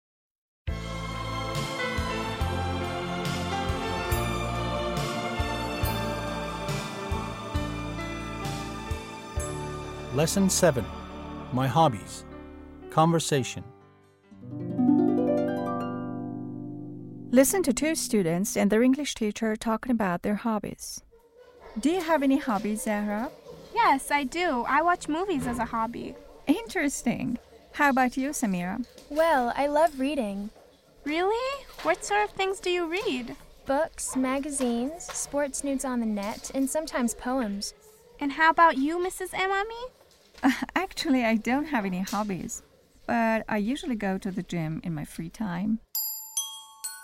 8-L7-Conversation
8-L7-Conversation.mp3